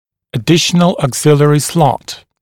[ə’dɪʃənl ɔːg’zɪlɪərɪ slɔt][э’дишэнл о:г’зилиэри слот]дополнительный вспомогательный паз